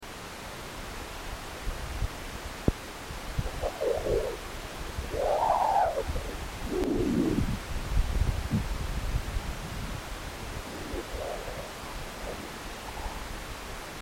A wind of fast moving particles blows out from our Sun, and although space transmits sound poorly, particle impact and variable-field data from NASA's near-Sun Parker Solar Probe is being translated into sound.
psp_dispersive_chirping_waves_1.mp3